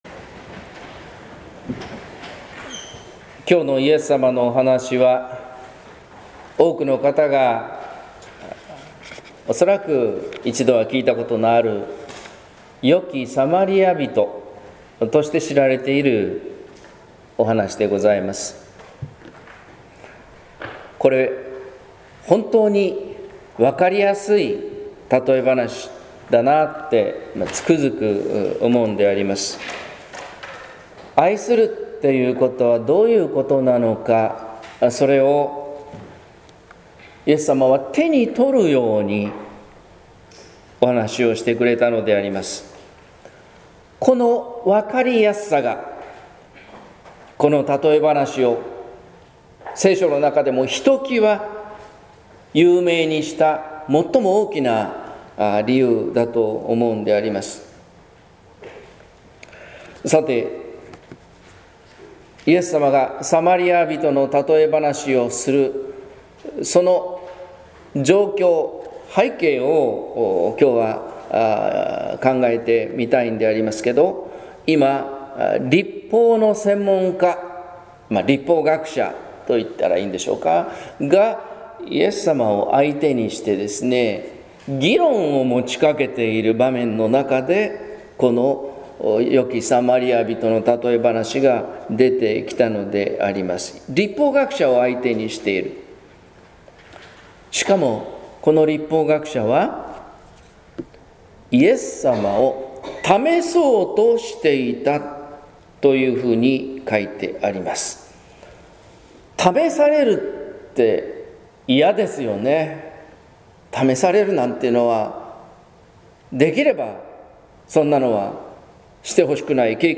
説教「神はわたしの隣人」（音声版） | 日本福音ルーテル市ヶ谷教会